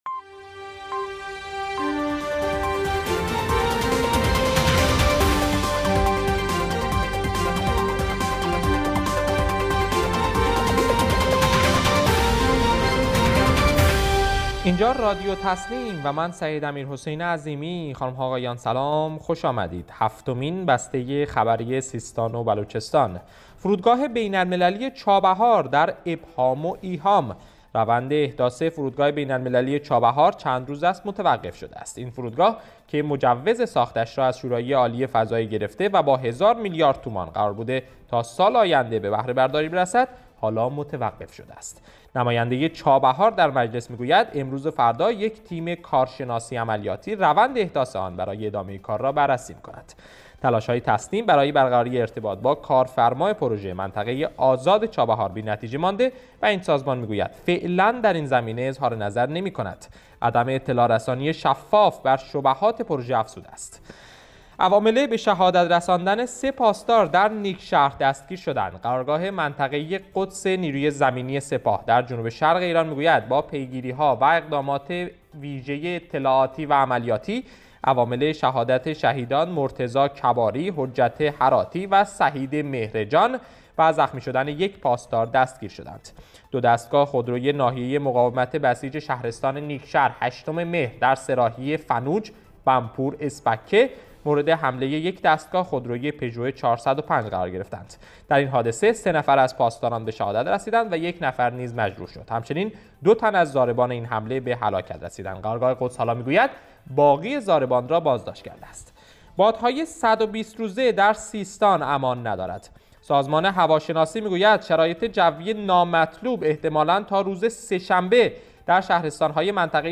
گروه استان‌ها- در هفتمین بسته خبری رادیو تسنیم سیستان و بلوچستان با مهم‌ترین عناوین خبری امروز همراه ما باشید.